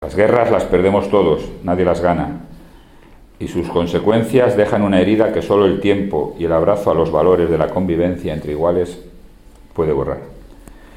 Declaraciones del alcalde José Luis Blanco 1 Declaraciones del alcalde José Luis Blanco 2 Vídeo del acto celebrado en el Ayuntamiento